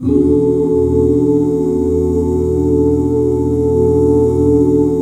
ESUS13 OOO-L.wav